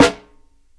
SNARE 2.wav